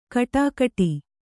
♪ kaṭākiṭi